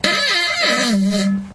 屁 " WS 30003
描述：fart poot gas flatulence flatulation explosion weird noise
标签： 爆炸 放屁 flatulation 胀气 废气 噪声 poot 怪异
声道立体声